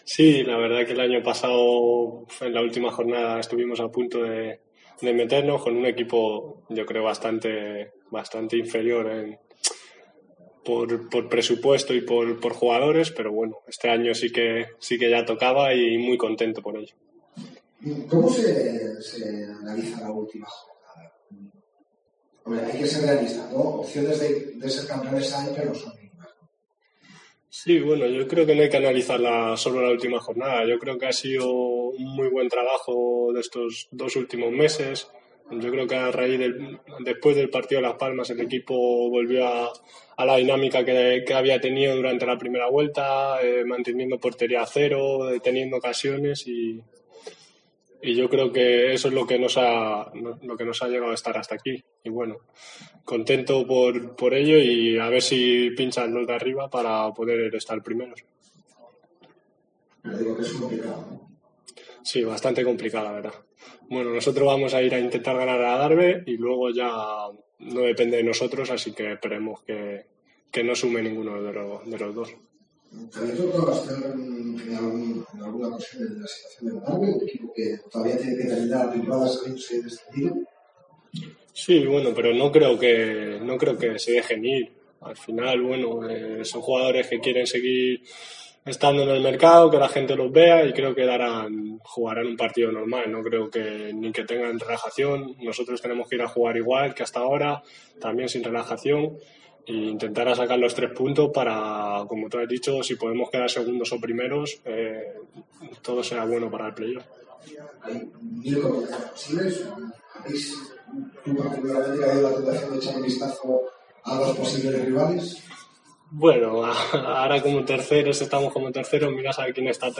Escucha aquí las palabras del jugador de la Deportiva Ponferradina